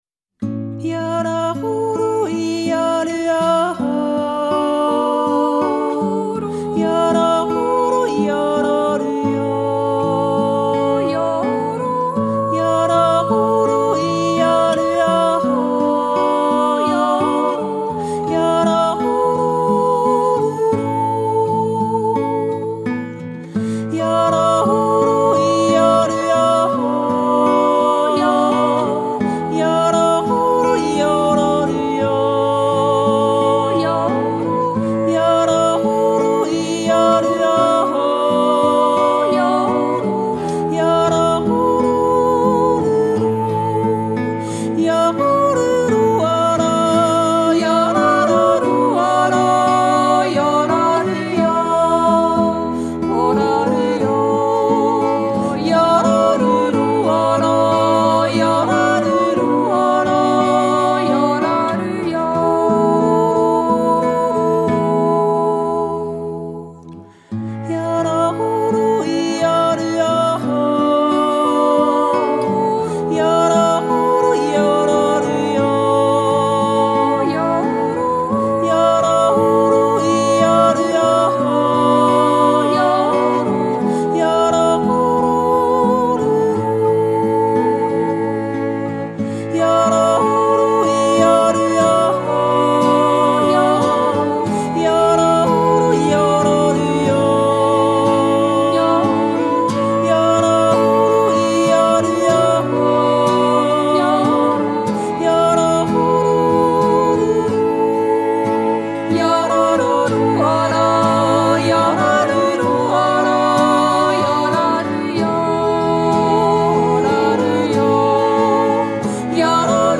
sch-pfungsjodler.mp3